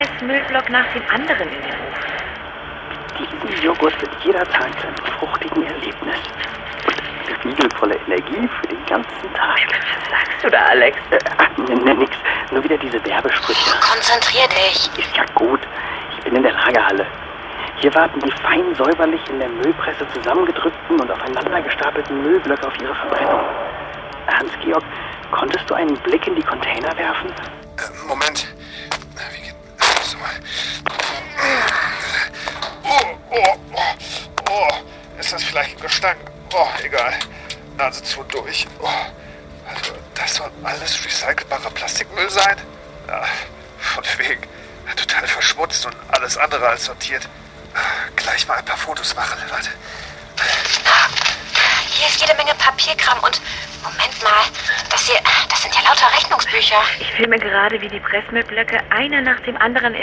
Hörspiele